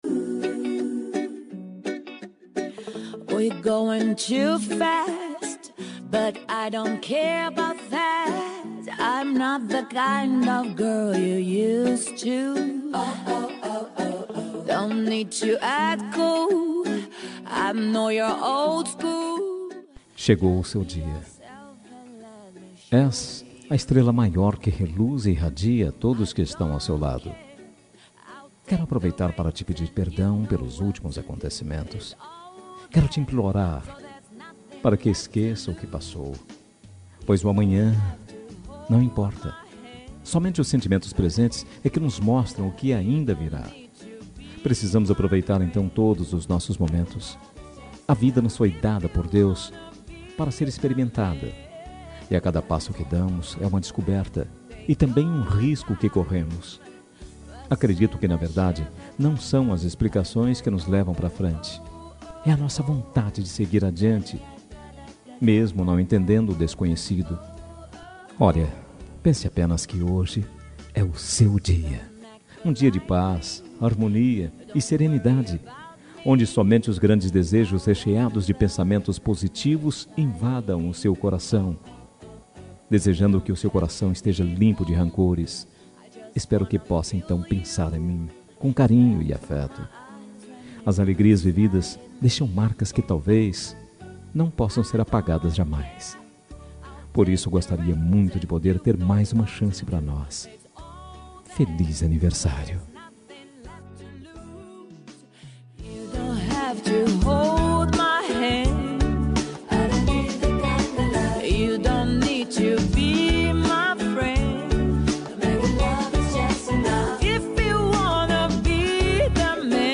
Aniversário Romântico com Reconciliação – Voz Masculina – Cód: 5461